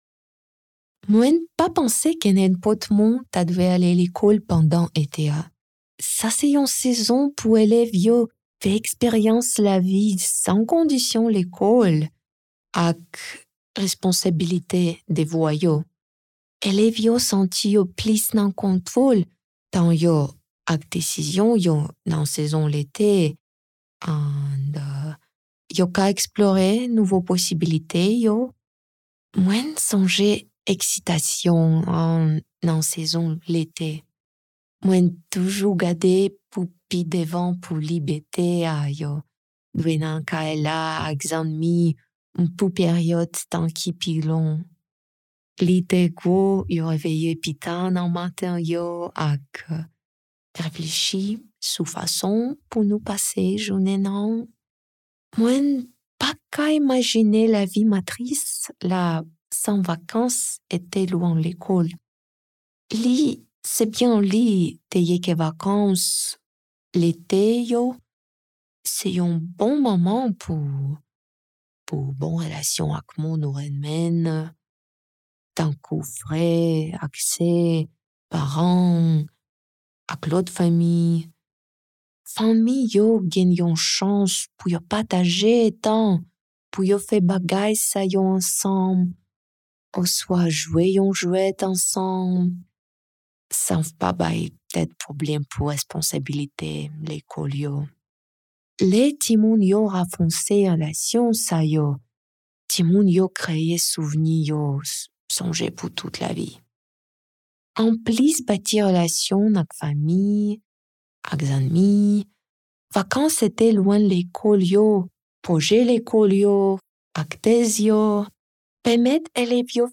Presentational Speaking: Haitian Creole
[Note: In the transcript below, ellipses indicate that the speaker paused.]